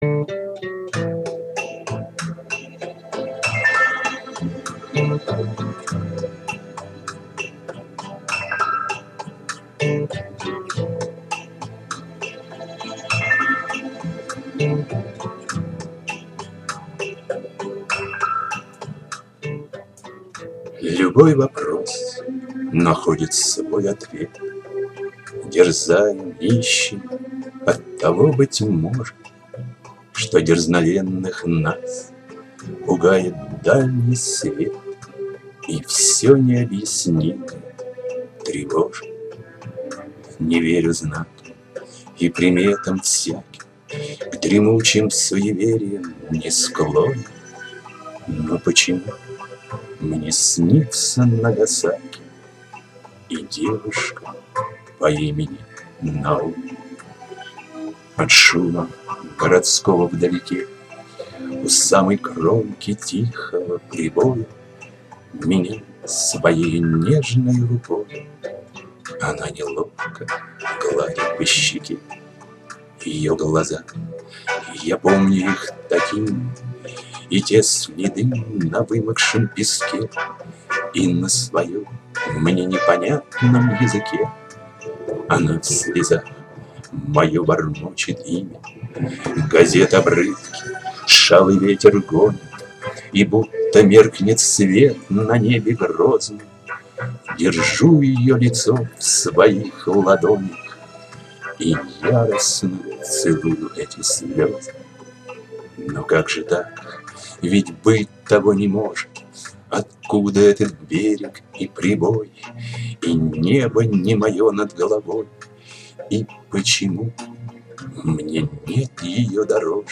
Доброй летней субботы всем завалинцам, чтобы немножко охладиться в этот жаркий день предлагаю немного аудиорифм, прошу прощения за качество это первые опытные записи.
К сожалению то примитивное устройство, которым я пользуюсь делает ямы и создается впечатление, что я проглатываю буквы, а то и слоги.
P.S. запись Девушки по имени Наоми я переделал, по декламации не знаю, но звук получше.